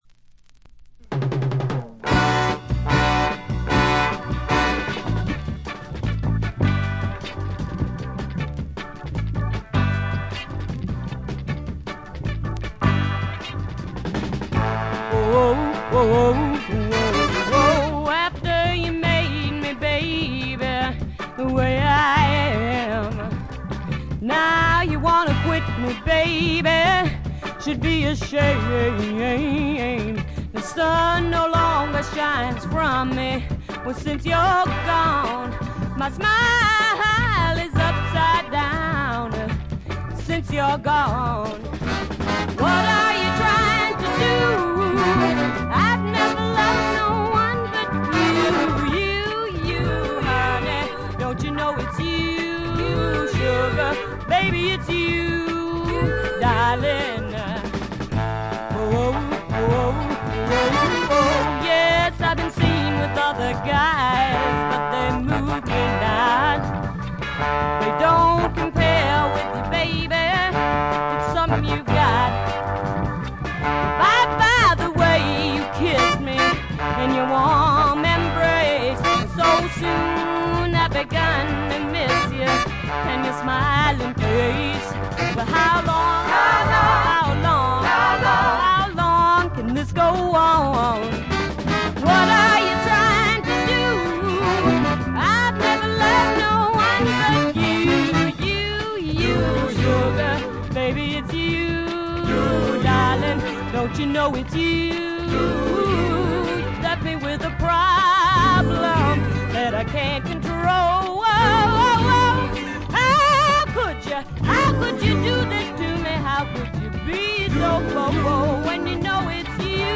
部分試聴ですが、軽微なチリプチ、バックグラウンドノイズ程度。
試聴曲は現品からの取り込み音源です。